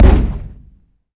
weapon_cannon_shot_02.wav